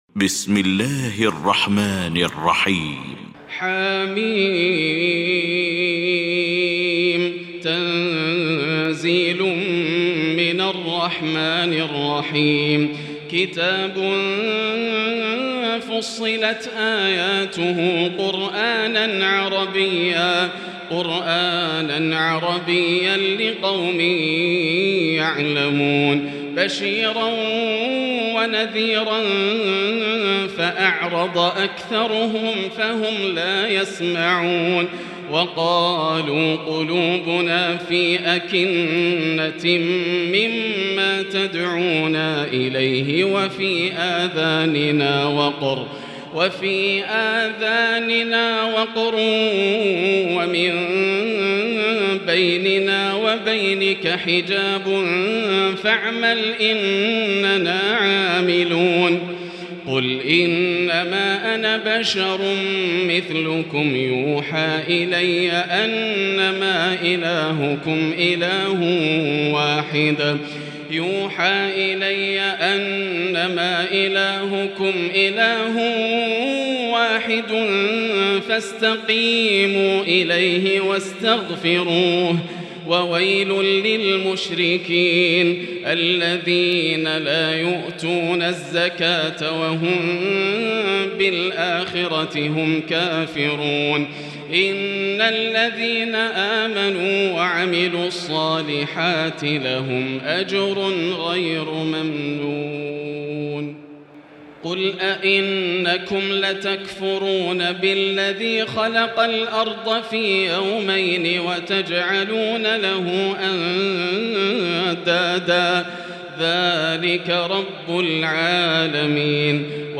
المكان: المسجد الحرام الشيخ: سعود الشريم سعود الشريم فضيلة الشيخ ياسر الدوسري فصلت